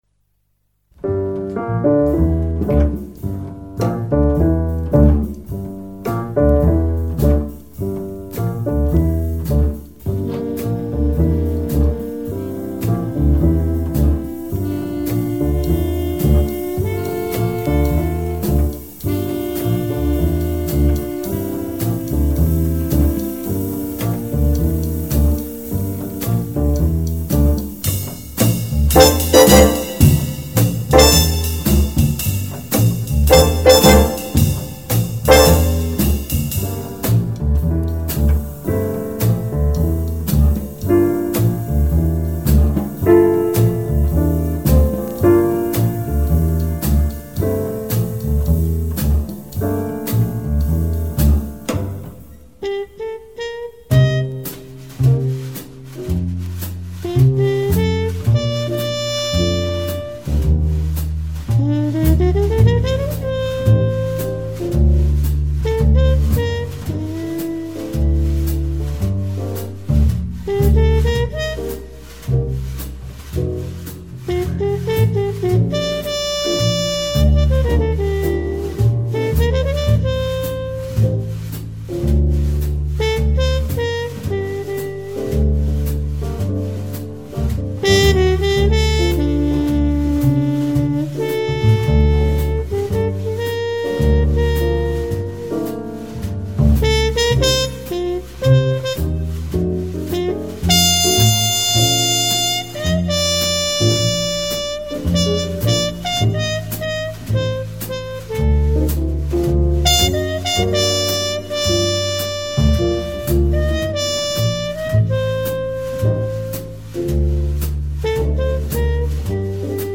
آلبوم جاز کلاسیک